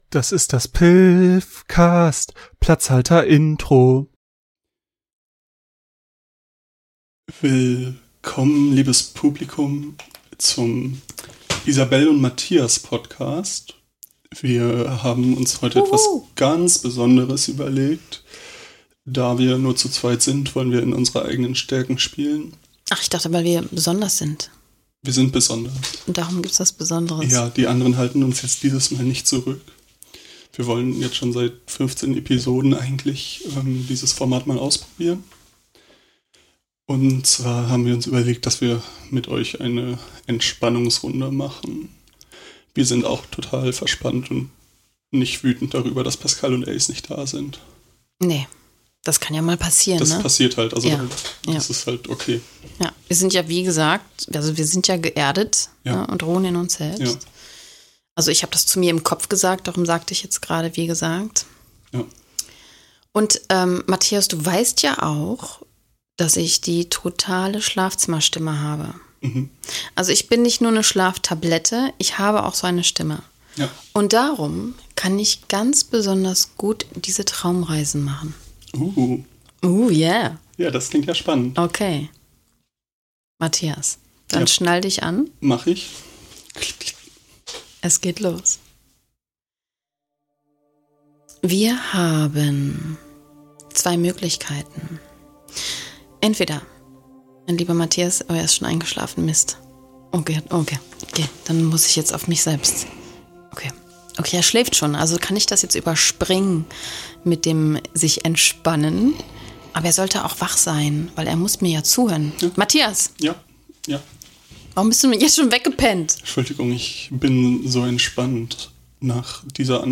Heute mal keine Comedy, sondern eine Gedankenreise! Lehnt euch zurück, entspannt euch und fahrt nicht Auto!